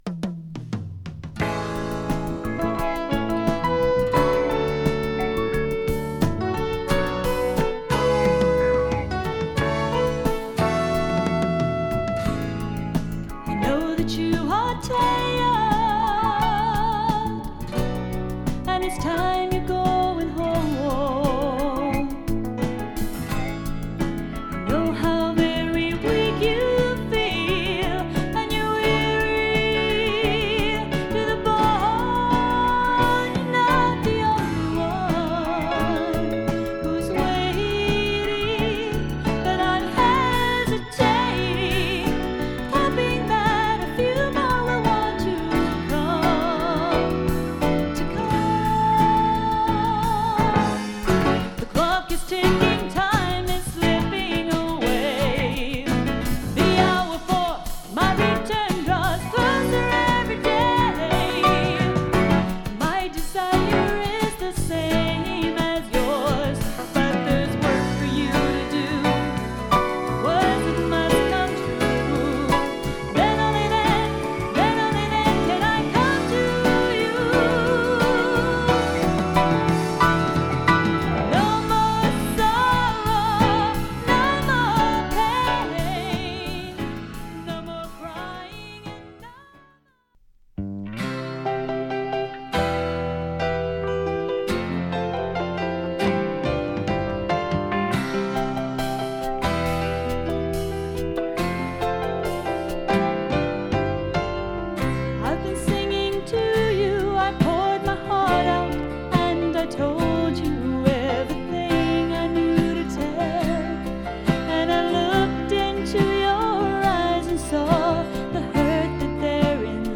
ウェットなメロディとソフティケイトな彼女の歌声が素晴らしい
サビの掛け合いコーラスが最高な